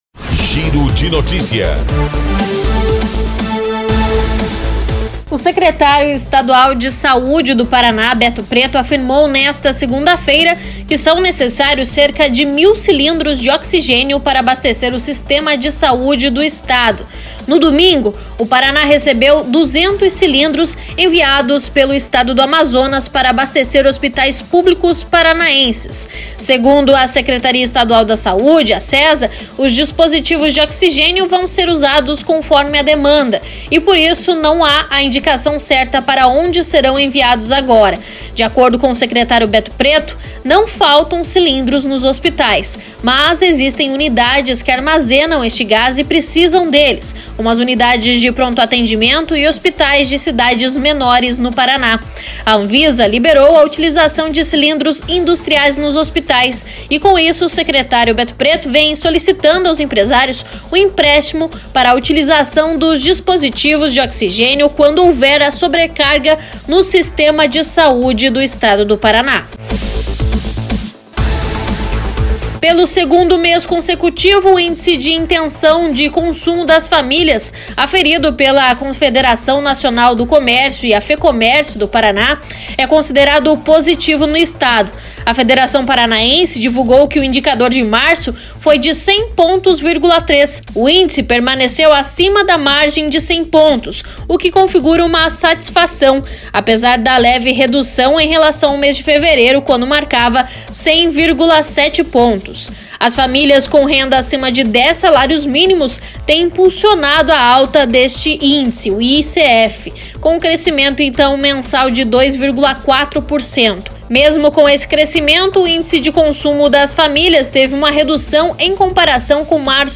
Giro de Notícias da manhã COM TRILHA